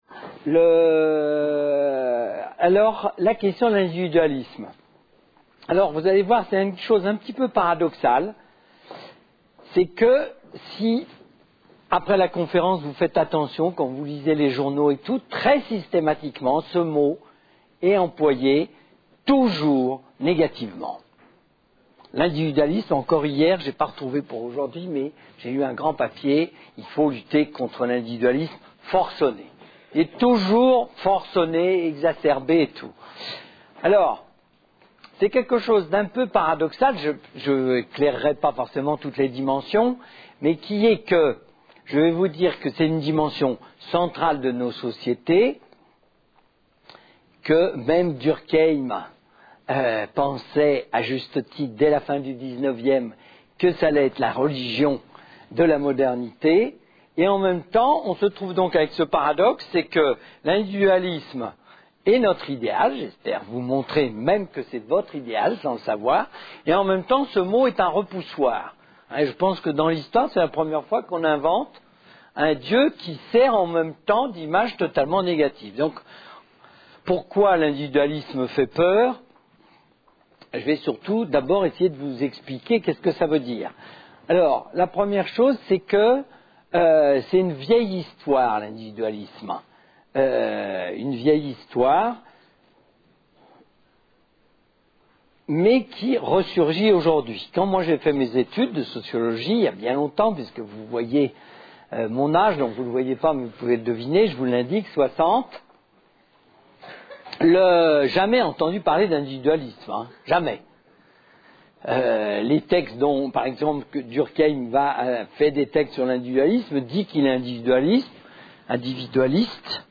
Une conférence de l'UTLS au Lycée L'individualisme par François de Singly Lycée Richelieu (92 Rueil Malmaison)